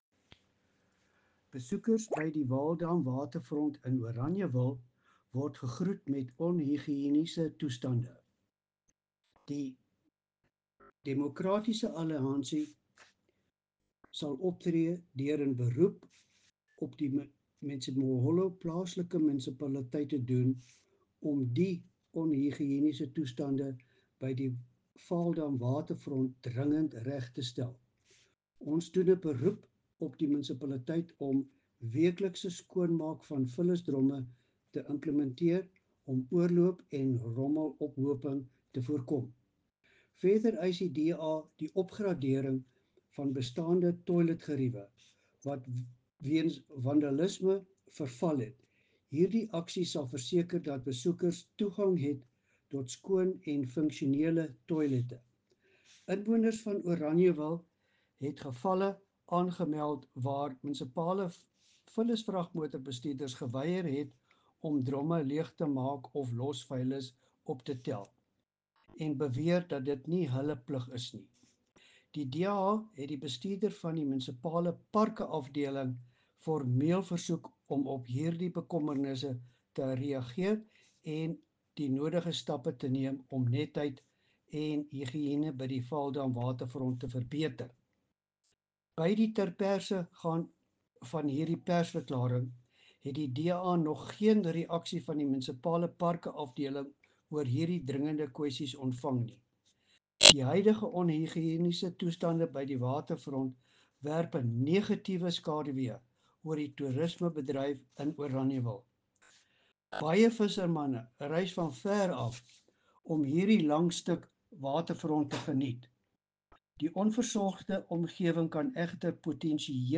Afrikaans soundbites by Cllr Louis van Heerden and